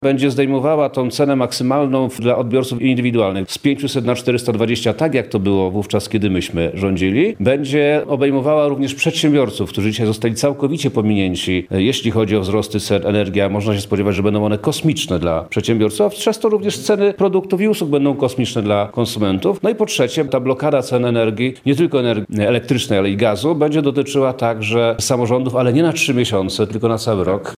Prawo i Sprawiedliwość apeluje, aby Sejm zajął się projektem ustawy „Stop Podwyżkom”. Podczas konferencji w Lublinie poseł Przemysław Czarnek zaapelował również do marszałka Szymona Hołowni o jak najszybsze procedowanie ustawy.